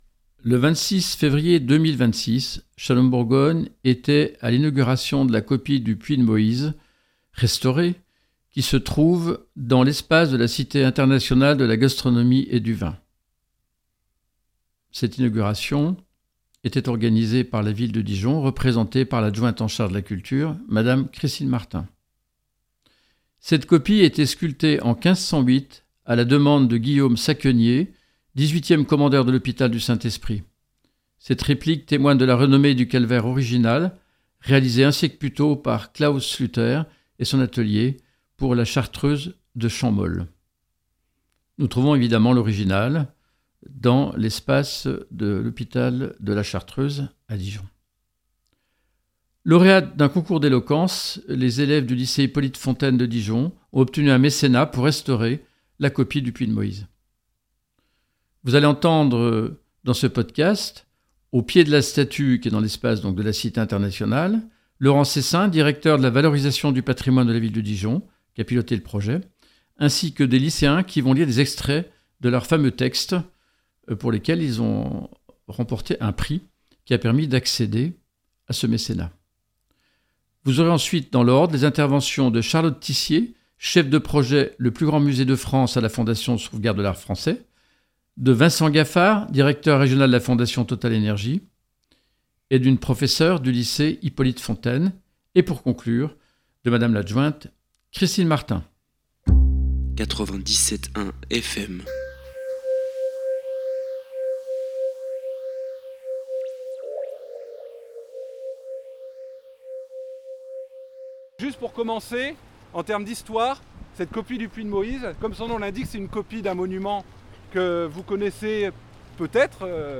La belle histoire des lycéens et du Puits de Moïse : Le 26 février 2026, « Shalom Bourgogne » était à l’inauguration de la copie duPuits de Moïse restaurée organisée par la Ville de Dijon représentée parl’adjointe en charge de la culture Christine Martin.